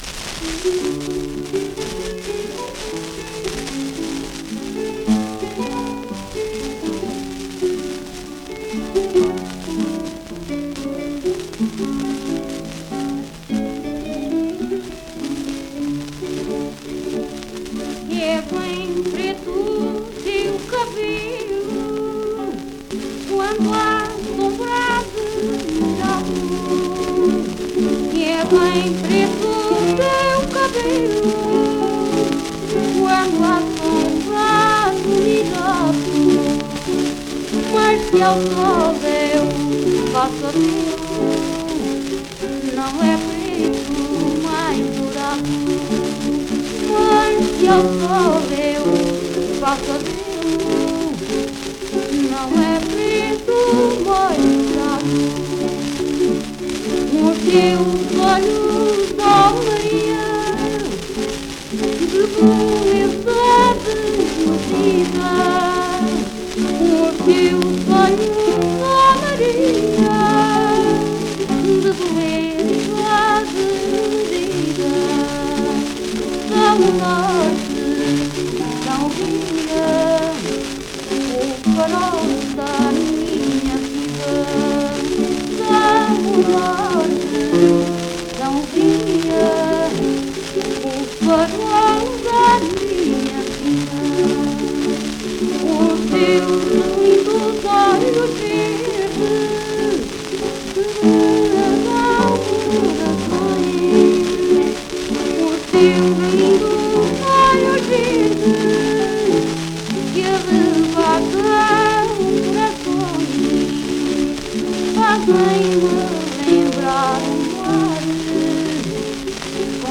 inetmd-fcsh-ifpxx-mntd-audio-fado_corrido-732.mp3